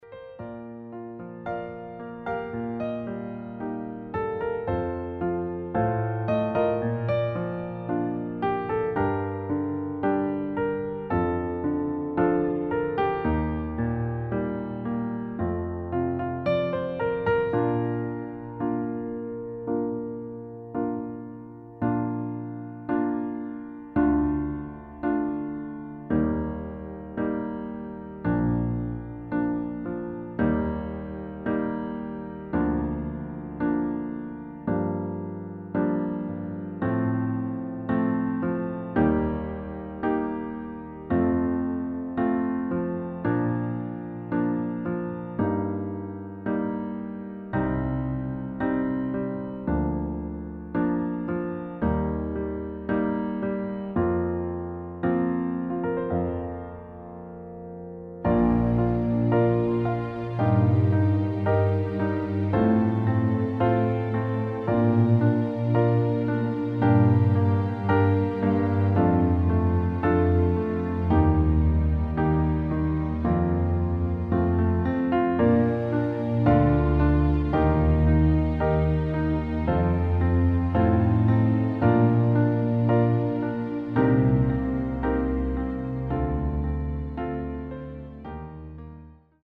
• Tonart: Bb Dur, C Dur
• Art: Klavierversion
• Das Instrumental beinhaltet NICHT die Leadstimme
Klavier / Streicher